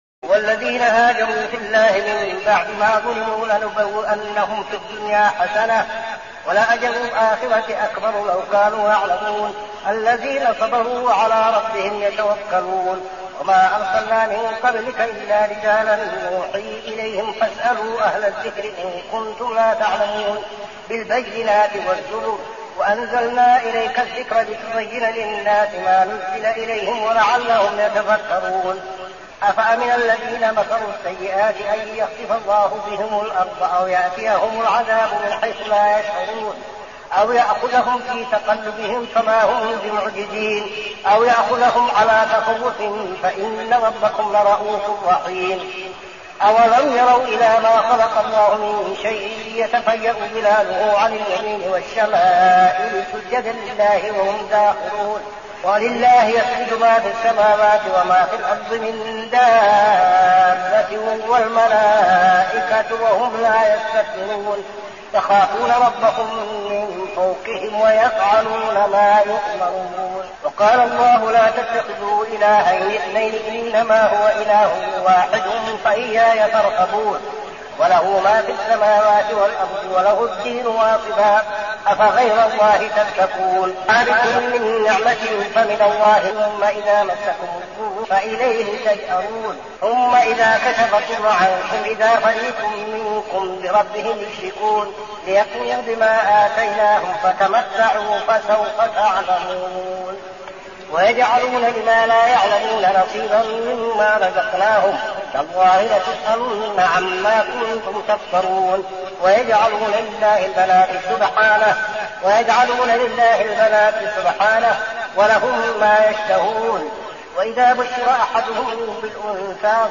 صلاة التراويح عام 1402هـ سورة النحل 41-128 | Tarawih prayer Surah An-Nahl > تراويح الحرم النبوي عام 1402 🕌 > التراويح - تلاوات الحرمين